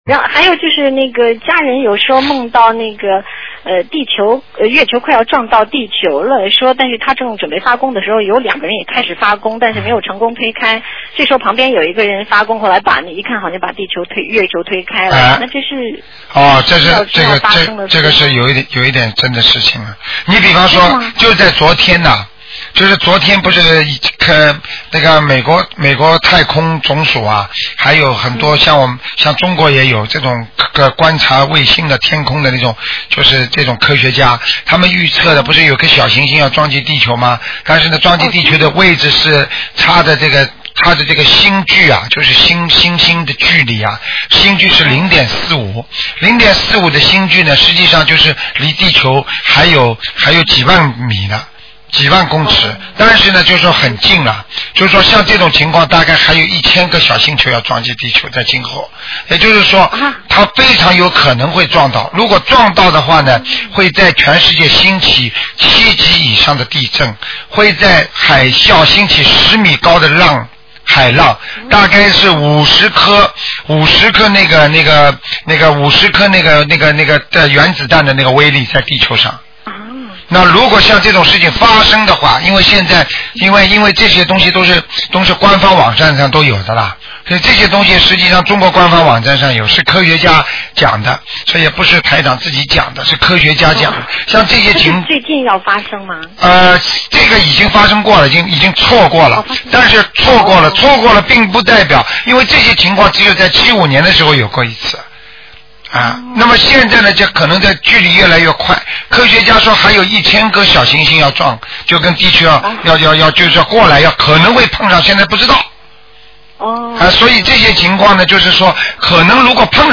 【问答节目敬请以音频为准，文字仅供参考】